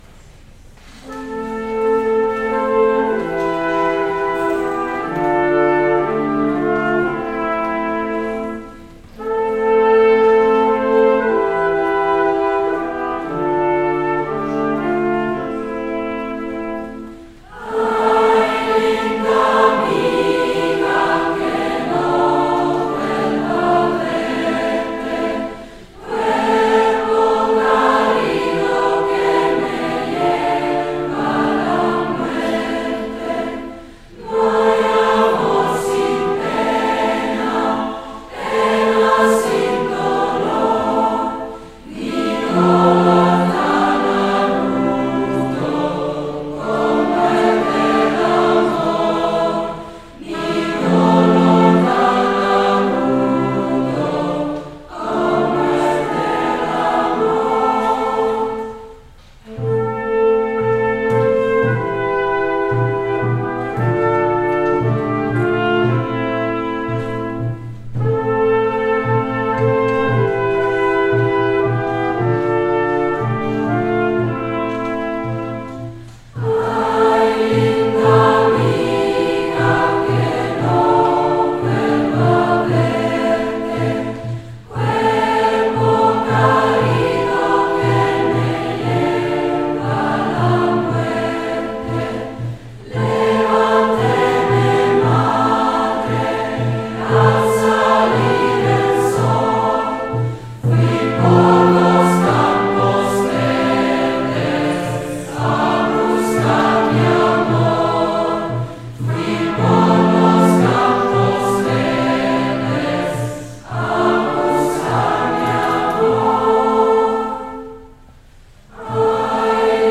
Sommerkonzert 2025 Sing, Sing, Sing!